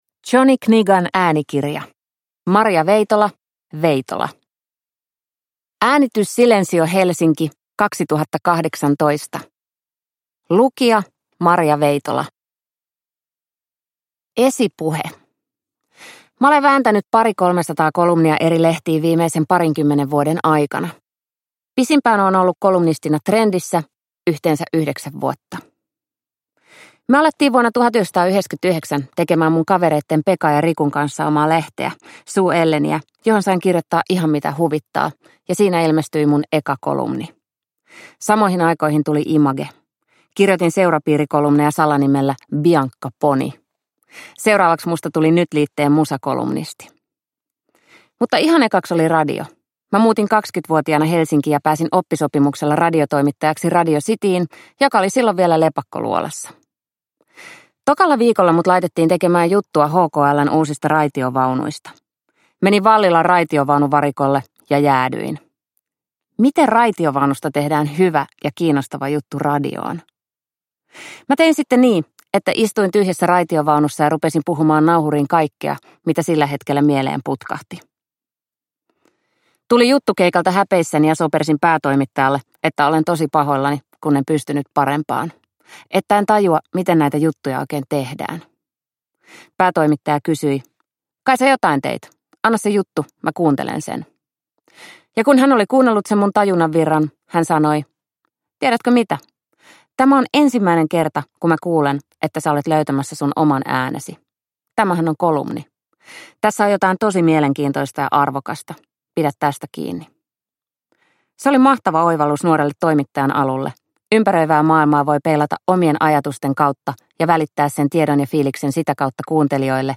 Veitola – Ljudbok – Laddas ner
Uppläsare: Maria Veitola